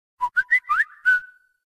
Kategorien: Soundeffekte